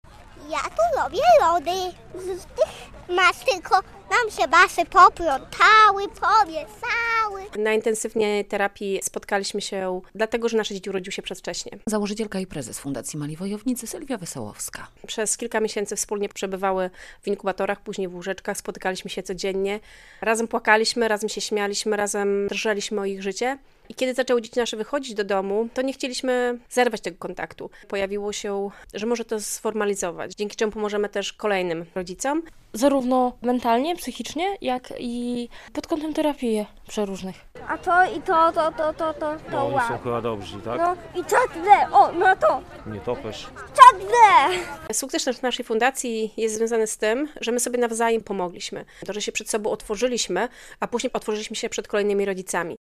Z tej okazji w sobotę (10.09) przy jej siedzibie w Białymstoku zorganizowano festyn.